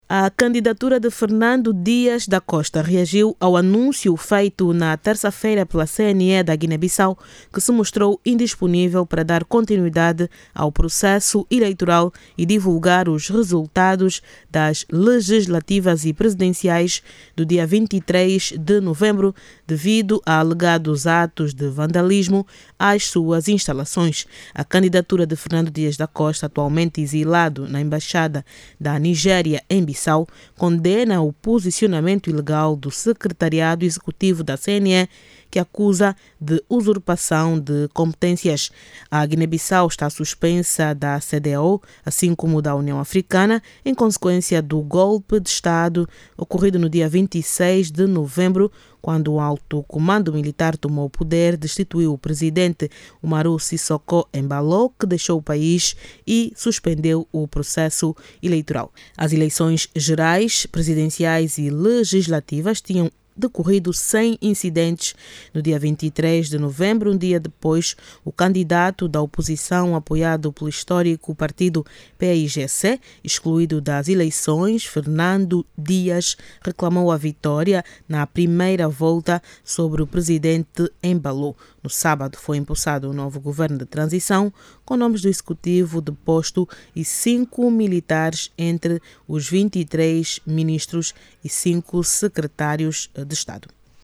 A candidatura de Fernando Dias, que reclama vitória nas presidenciais na Guiné-Bissau, exige à Comissão Nacional de Eleições a convocação da plenária do órgão para que os resultados eleitorais sejam declarados “o mais rápido possível”. Fernando Dias, acusa o secretariado executivo da CNE, de usurpação de competências. Saiba mais dados no áudio abaixo com a repórter